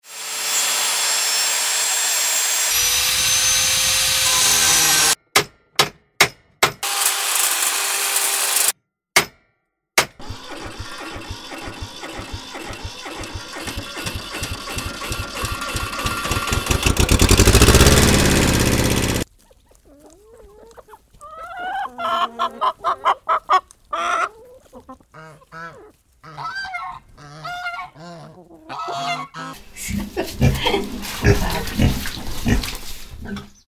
sound for video makers, relaxation, installation etc…